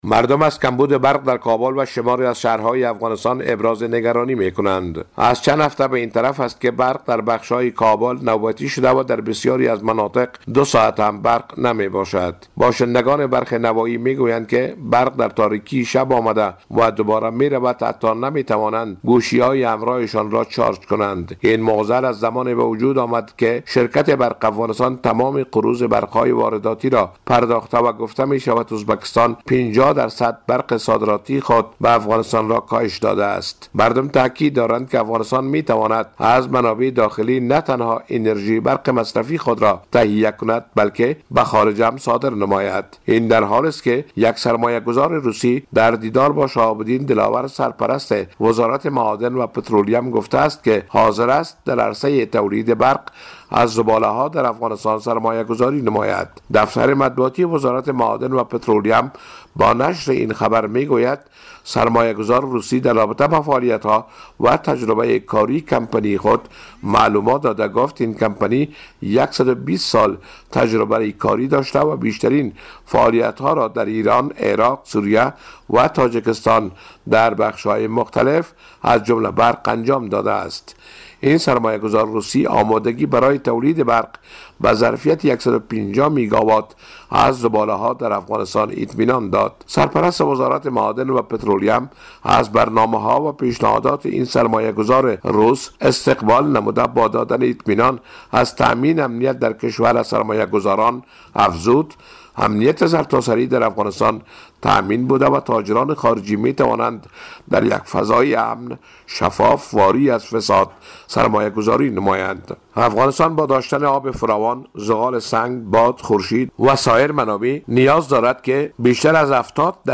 گزارش